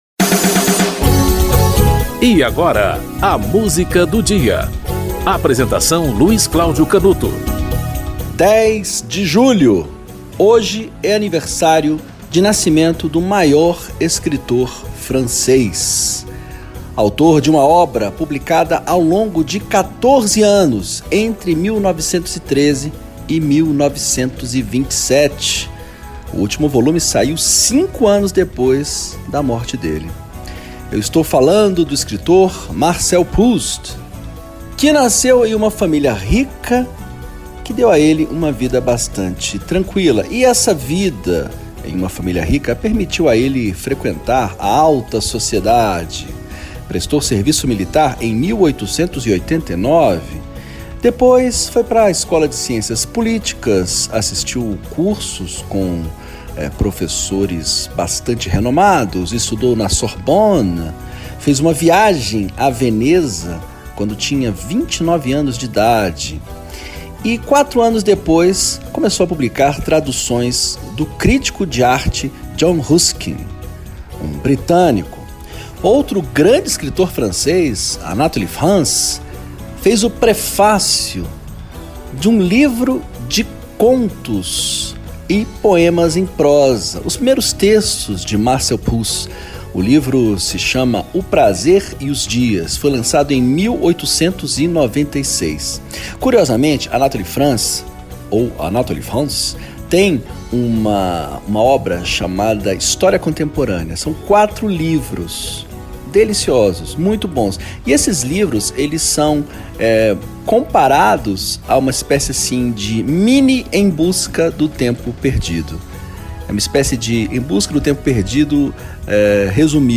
piano
violino) - Concerto para Violino e Piano